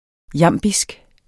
Udtale [ ˈjɑmˀbisg ]